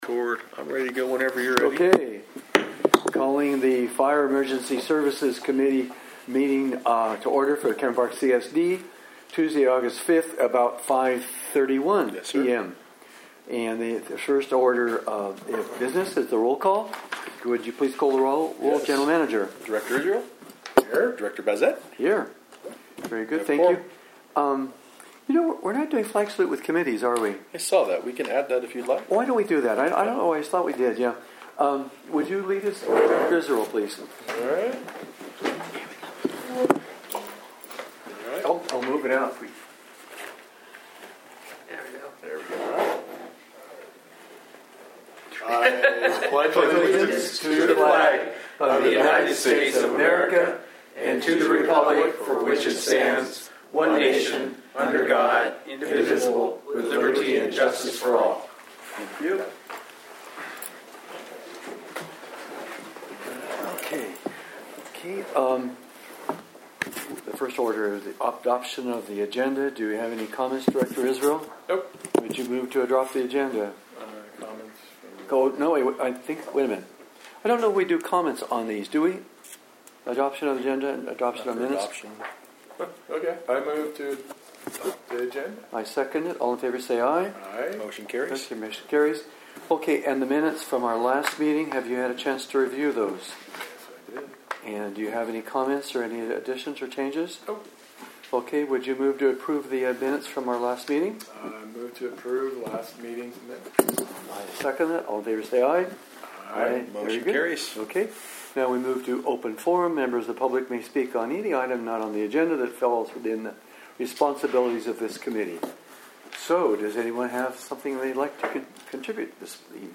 Fire and Emergency Services Committee Regular Meeting